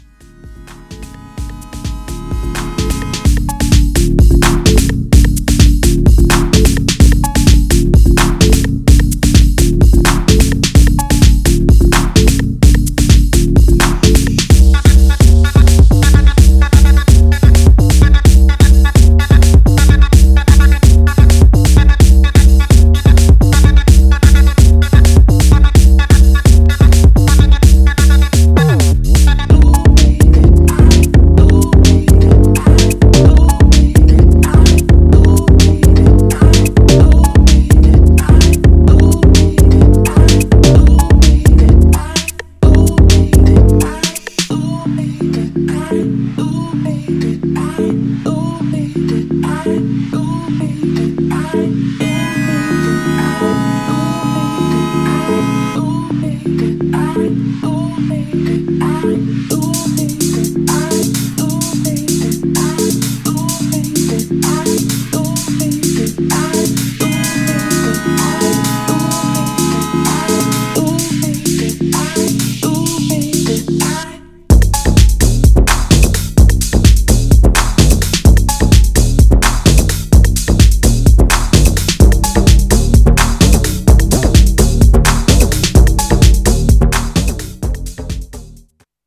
Styl: Dub/Dubstep, House, Techno, Breaks/Breakbeat